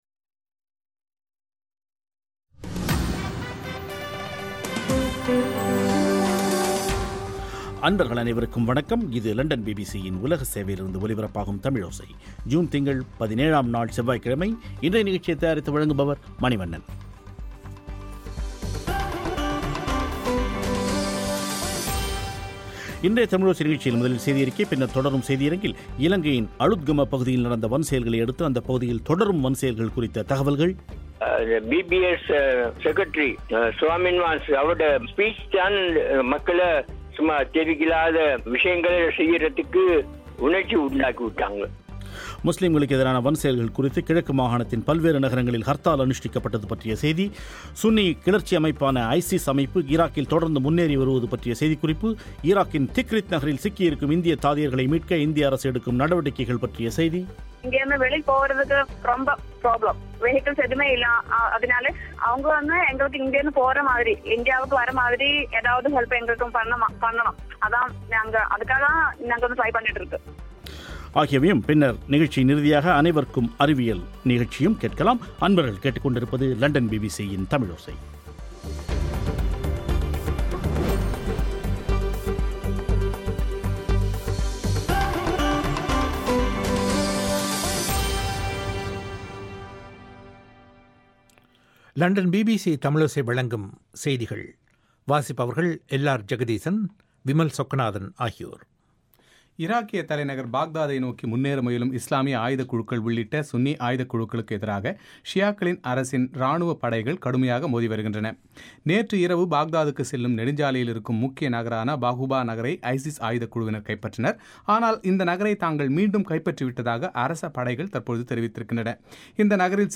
வன்முறைகளுக்கு பொதுபல சேனாவின் பொதுச் செயலரின் பேச்சே காரணம் என்று கூறும் அமைச்சர் ஃபௌசியின் பேட்டி.
அங்கு இருக்கும் கேரளாவைச் சேர்ந்த ஒரு செவிலியரின் பேட்டி.